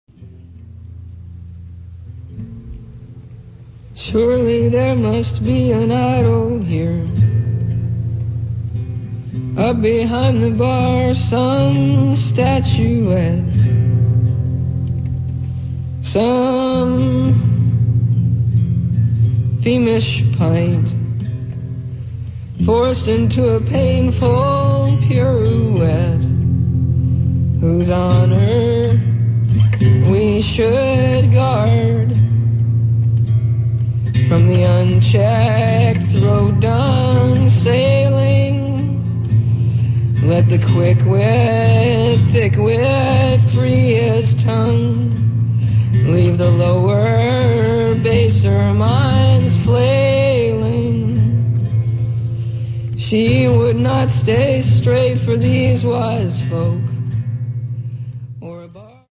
Sessions radios & lives inédits